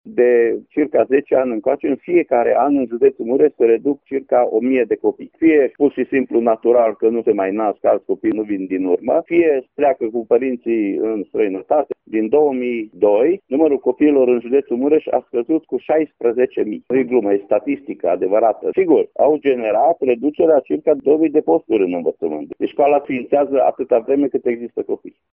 Revine inspectorul școlar general al judeţului Mureș, Ștefan Someșan: